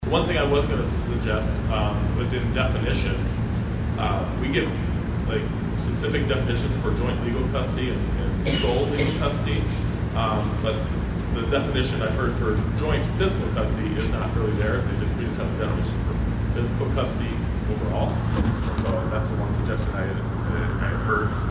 Senator Tom Pischke who introduced legislation back in 2021 to help create this panel discuss clarifying legal definition for various custodies.